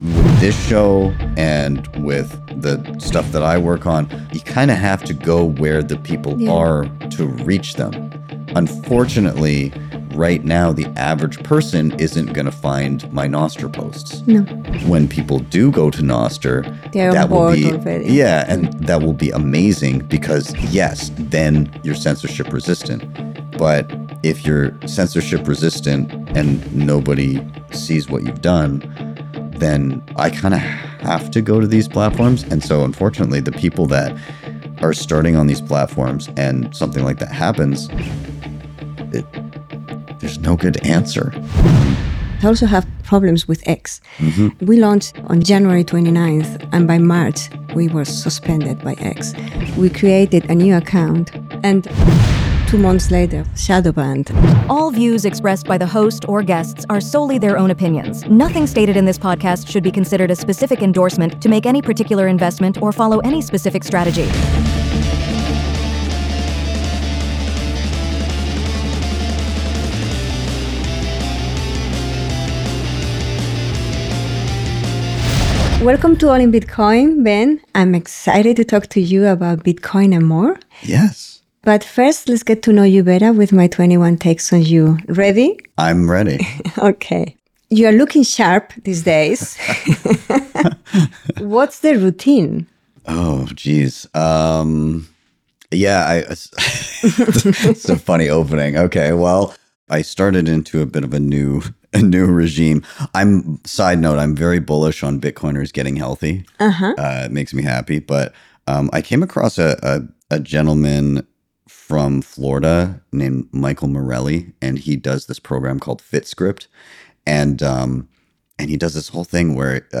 ALL IN BITCOIN is a high-quality podcast featuring in-person interviews with world-leading Bitcoiners, Nostriches, Austrian economists, and FreedomTech experts and pioneers.